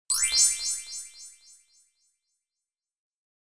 soundLevelComplete.mp3